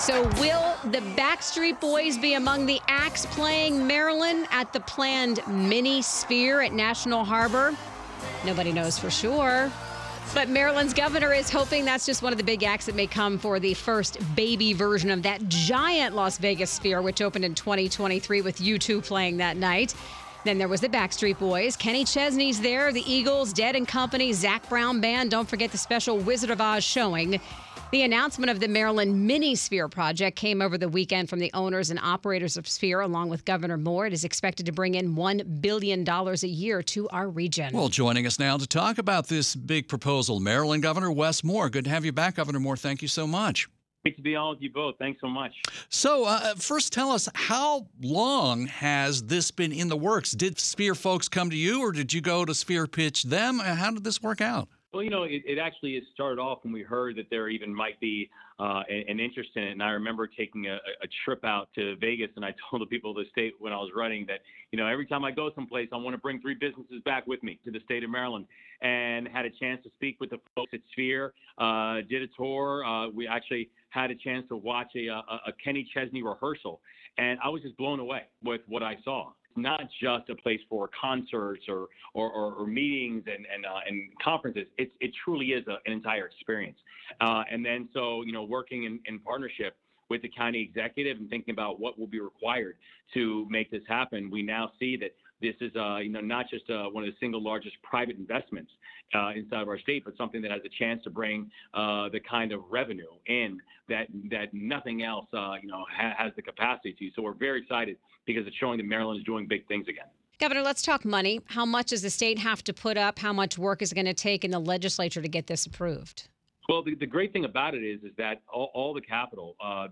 wes-moore-liveshot.wav